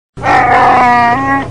Chewbacca ROAR Meme Effect sound effects free download